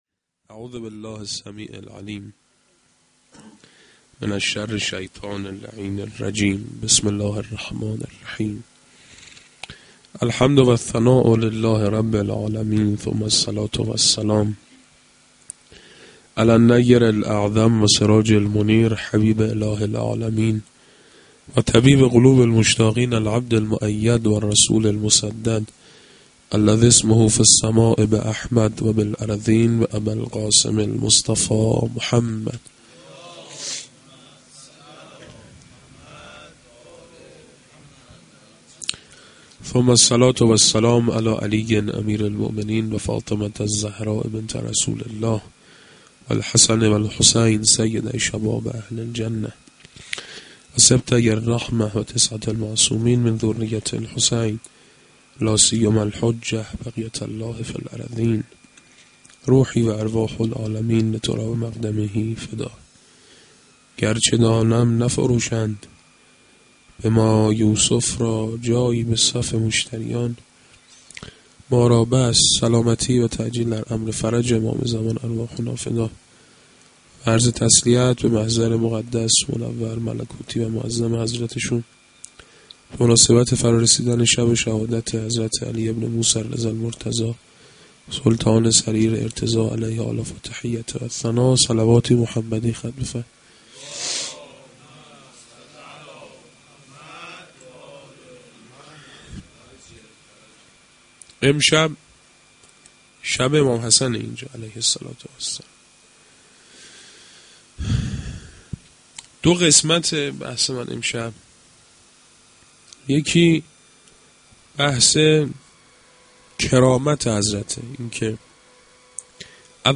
sa92-sh2-Sokhanrani.mp3